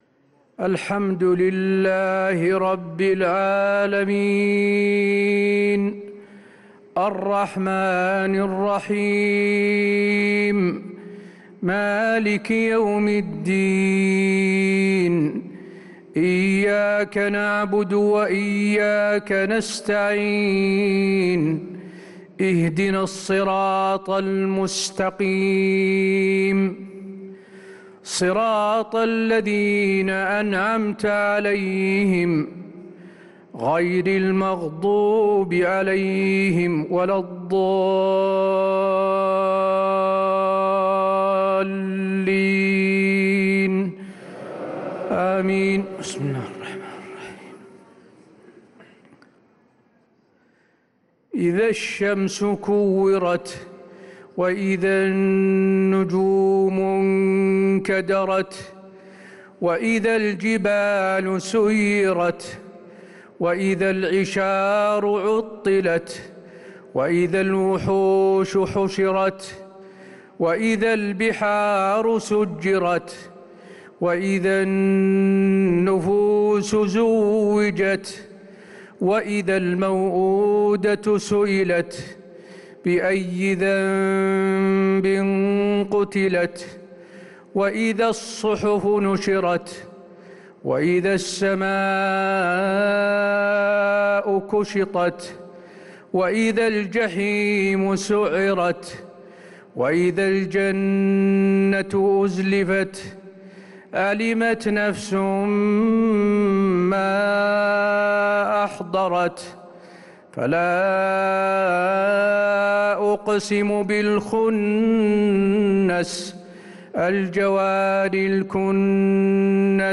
صلاة العشاء للقارئ حسين آل الشيخ 24 رمضان 1445 هـ